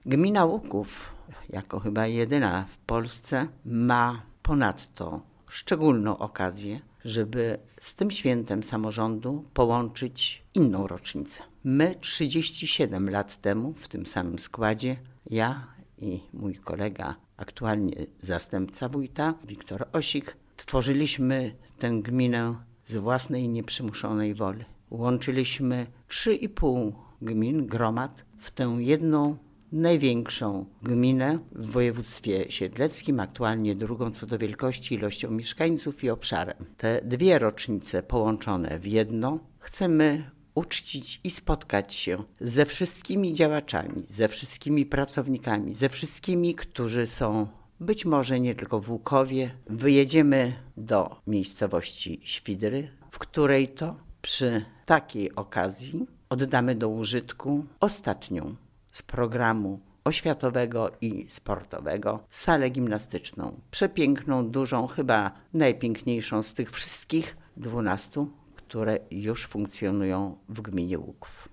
Wójt Kazimiera Goławska uważa, że oprócz 20-lecia samorządu okazji do świętowania jest więcej: